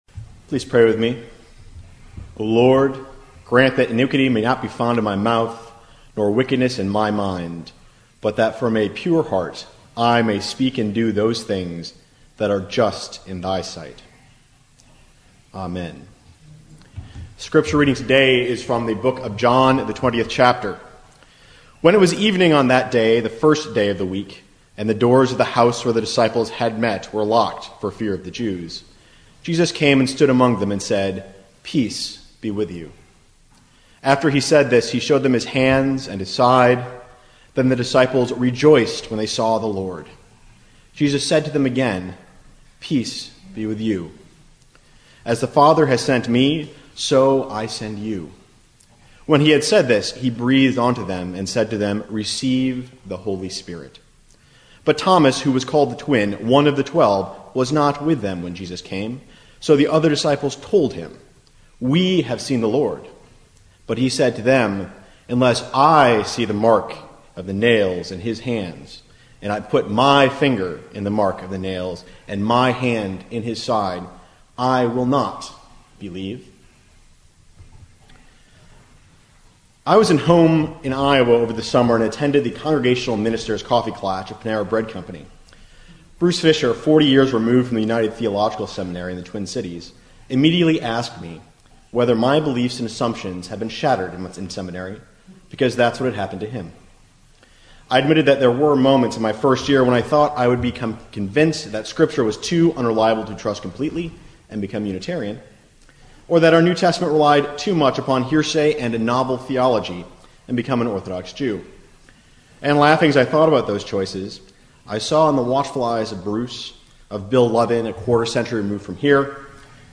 Billings Prize Preliminaries Sermon